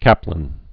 (kăplĭn)